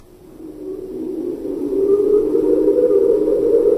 wind howling 3.ogg
Original creative-commons licensed sounds for DJ's and music producers, recorded with high quality studio microphones.
[wind-howling]-_sbo.mp3